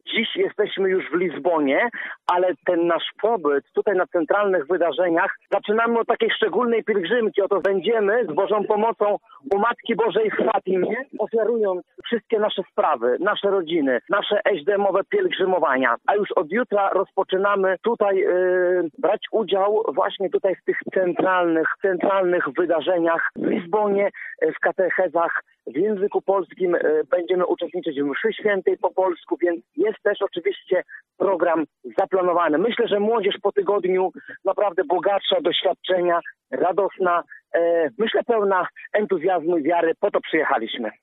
relacjonuje wydarzenia.